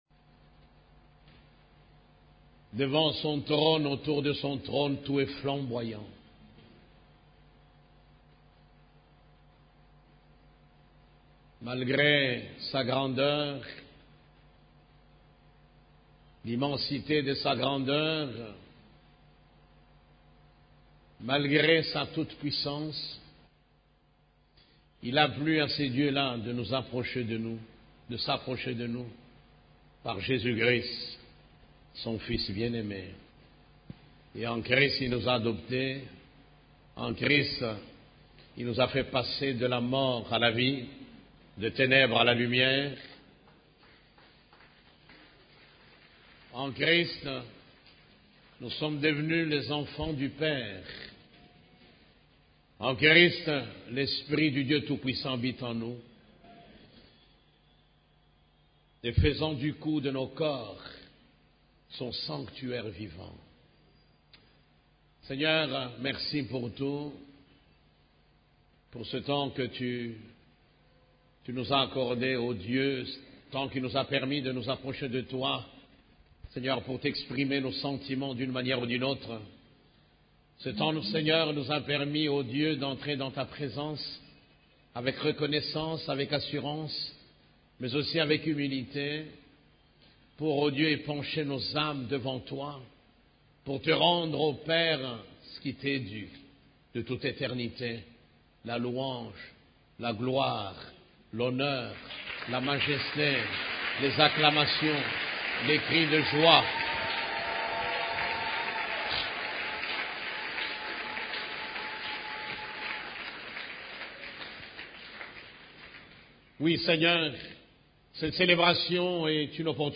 CEF la Borne, Culte du Dimanche, Comment faire face à l'adversité